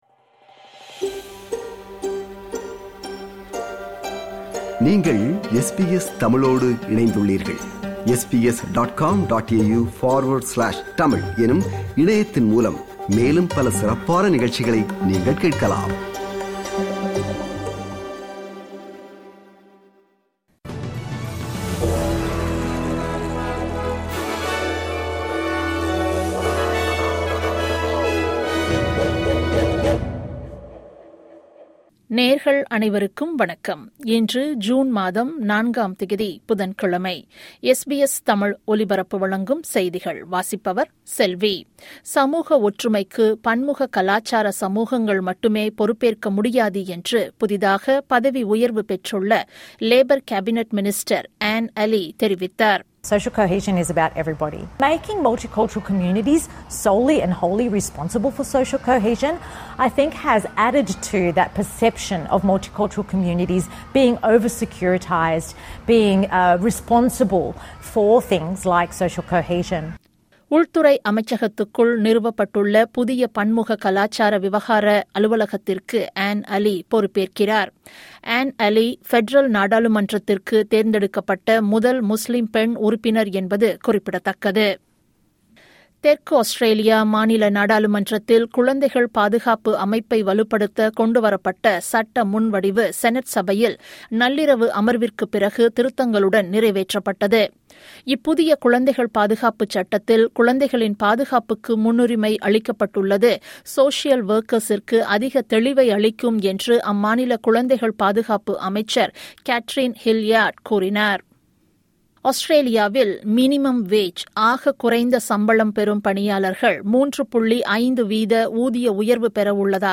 SBS தமிழ் ஒலிபரப்பின் இன்றைய (புதன்கிழமை 04/06/2025) செய்திகள்.